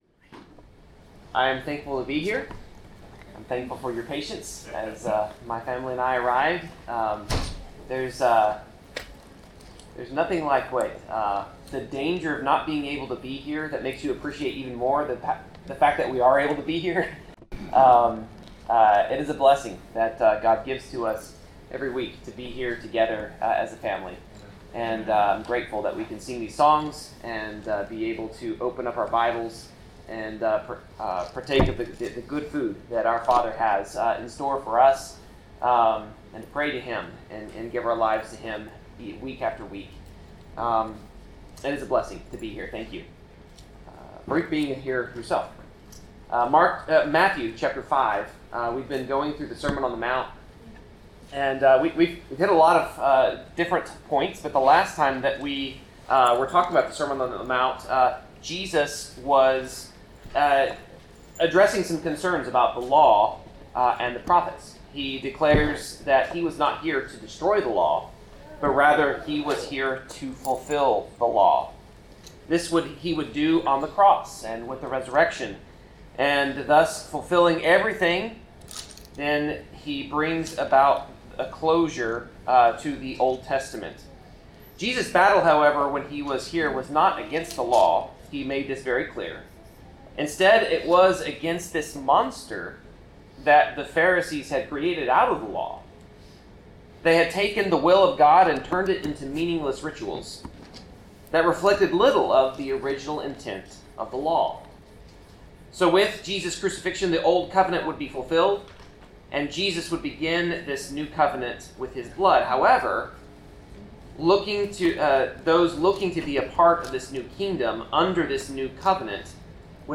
Passage: Matthew 5 Service Type: Sermon